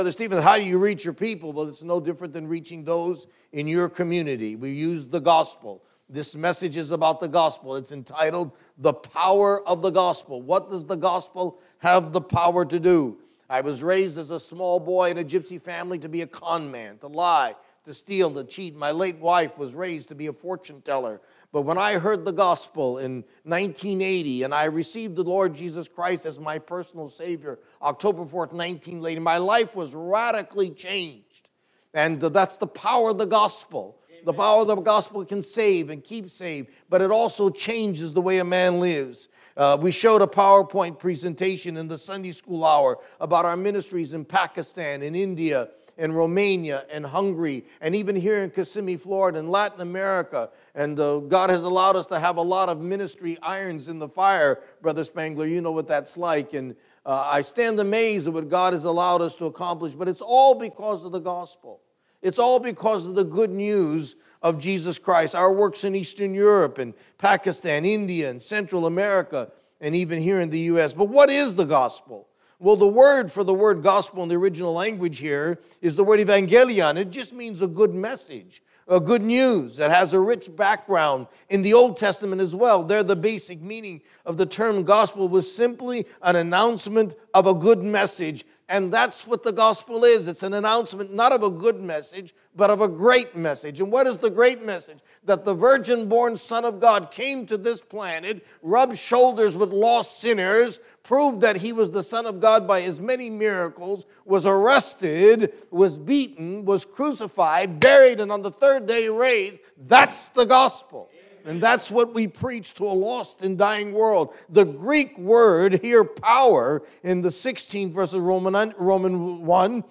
Messages from visiting missionaries.